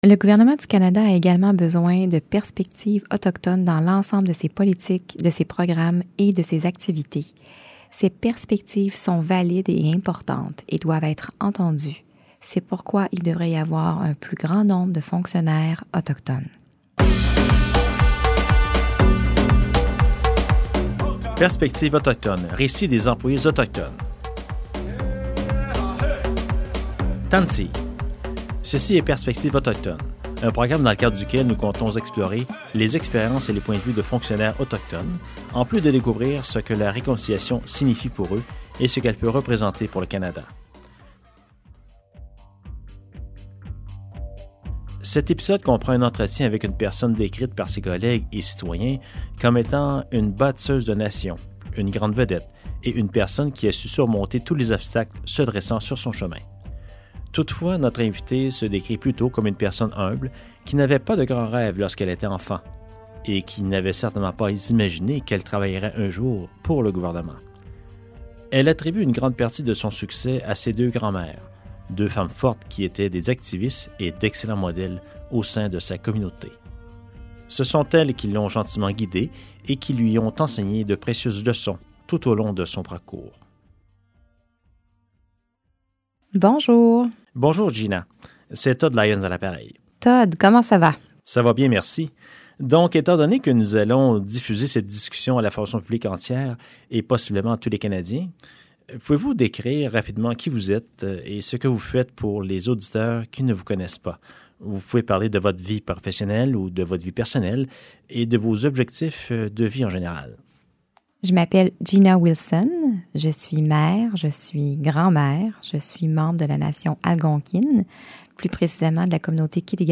Enjeu 5 - Conversation avec Gina Wilson, Sous-ministre de la condition féminine du Canada et championne des employés autochtones fédéraux
La baladodiffusion Perspectives autochtones d’EDSC traite des réalités des employés autochtones dans la fonction publique ainsi que de la signification de la réconciliation pour eux et de ce qu’elle pourrait représenter pour le Canada. Des fonctionnaires y témoignent de leurs expériences en tant qu’Autochtones et des difficultés qu’ils doivent surmonter à cet égard.